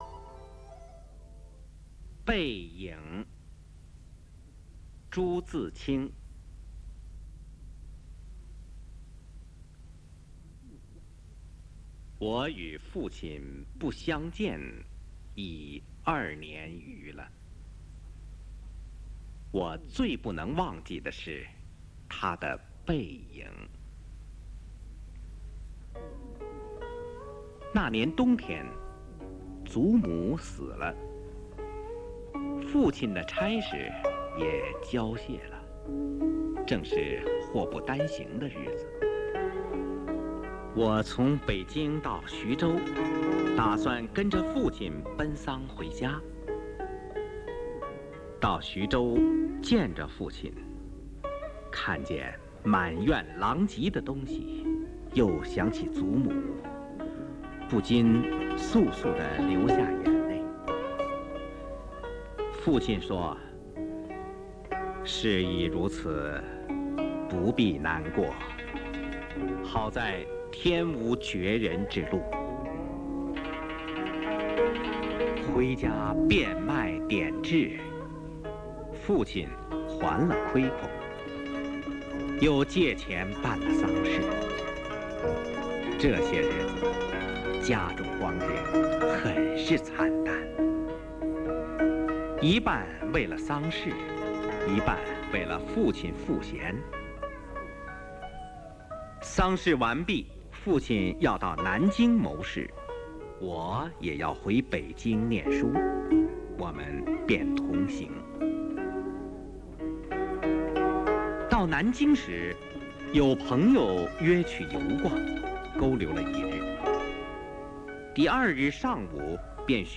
《背影》男声朗读